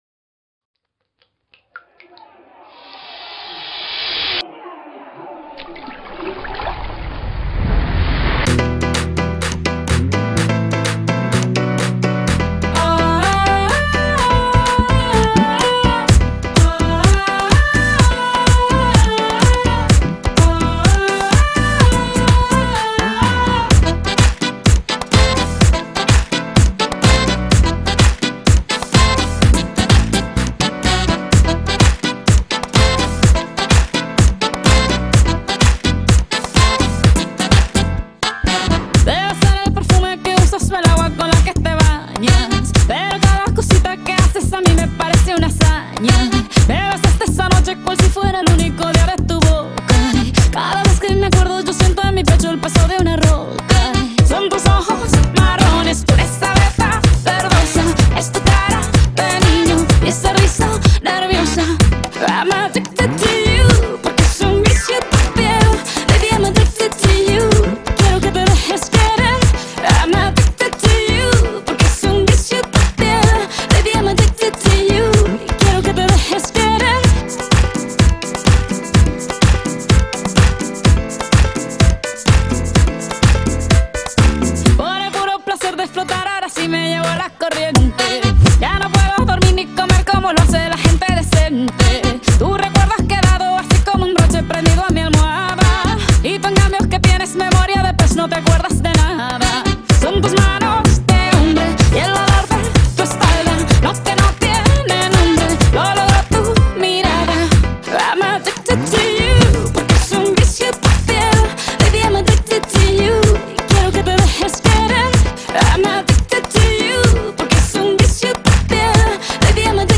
Pop, Dance